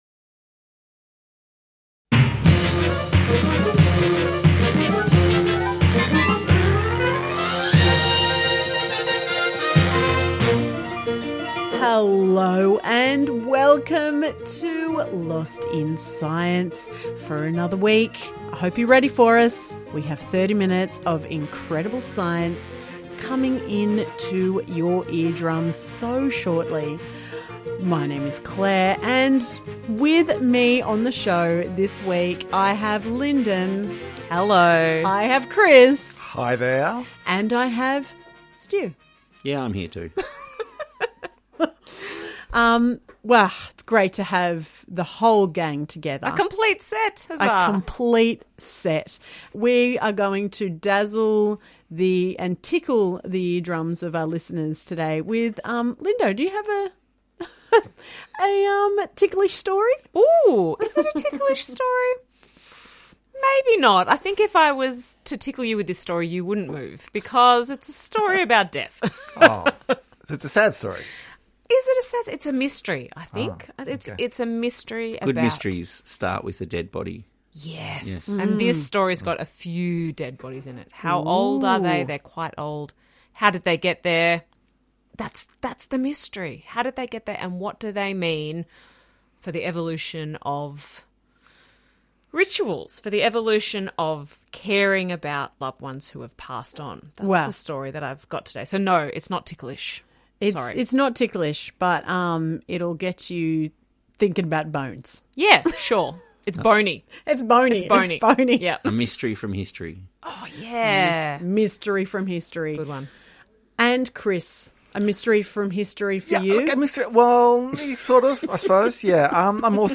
Tweet Lost in Science Thursday 8:30am to 9:00am Entertaining news and discussion about research that has impact on society and providing a wide range of science and technology news.